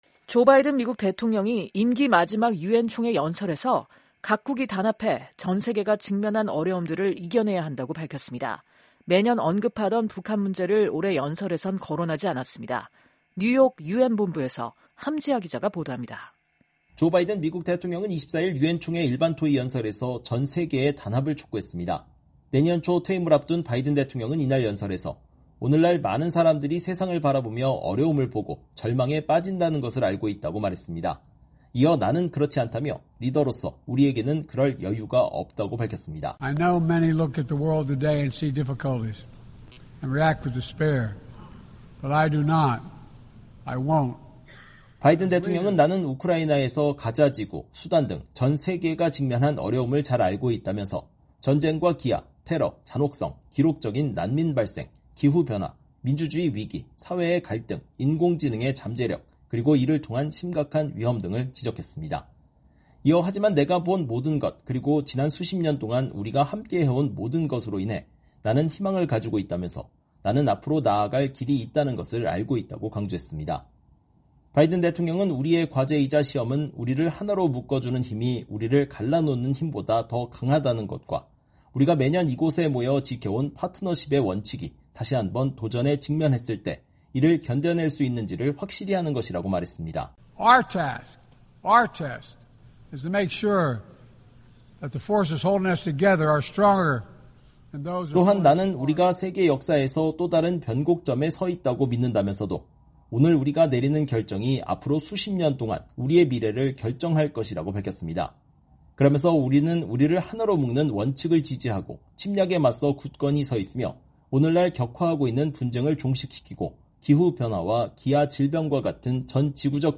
조 바이든 미국 대통령이 24일 미국 뉴욕 유엔 본부에서 열린 제79차 유엔 총회에서 연설하고 있다.